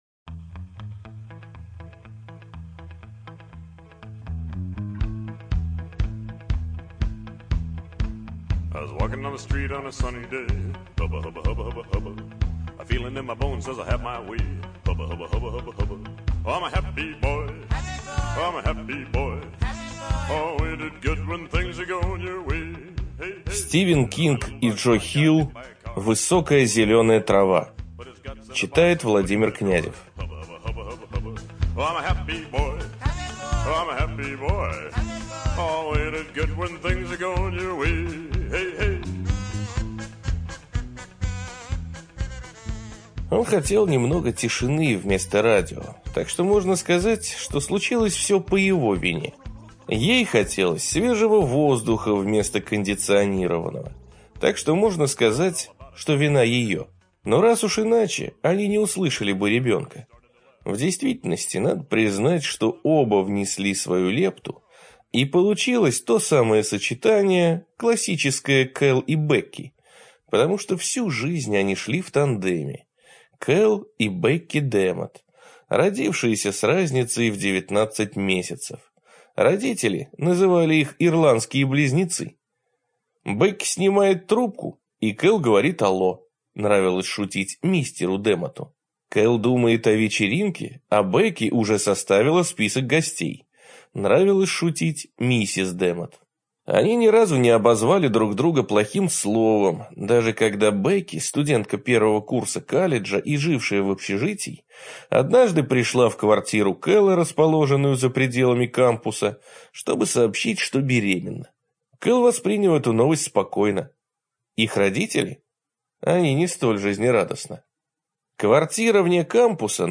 ЖанрУжасы и мистика